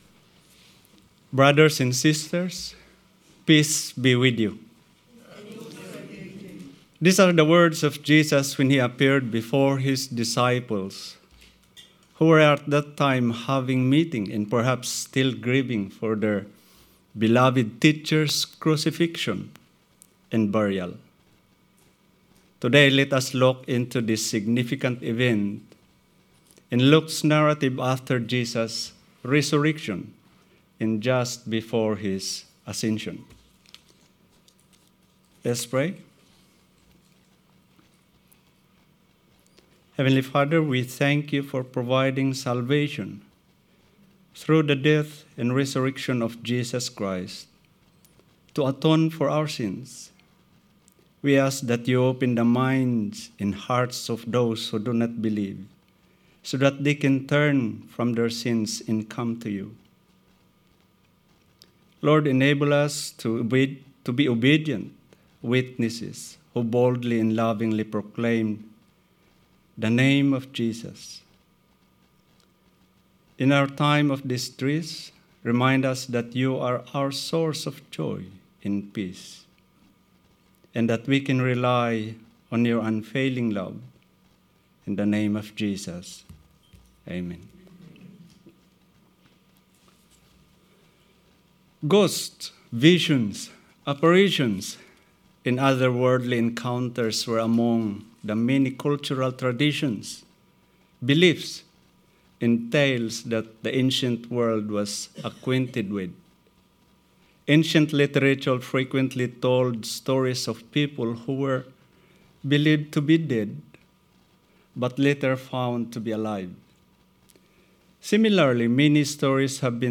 Sermon 14th April – A Lighthouse to the community